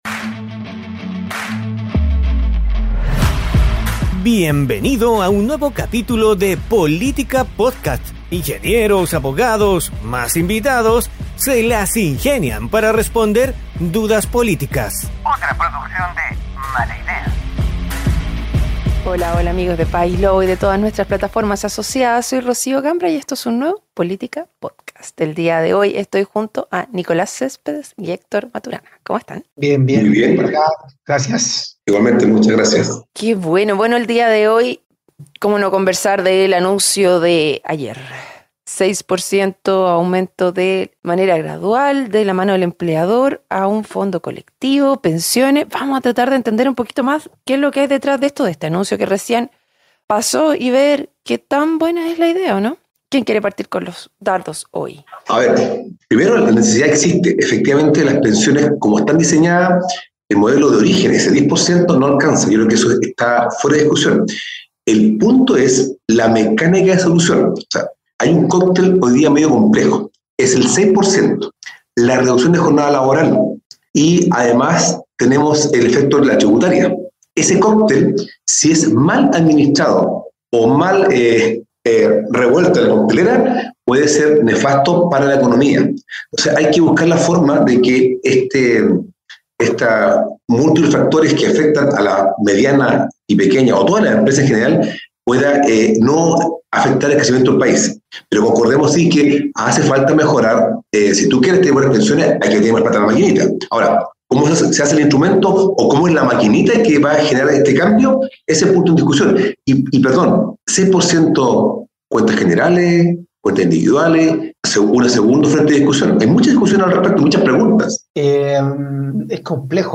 Ingenieros y abogados, más invitados, se las ingenian para responder dudas políticas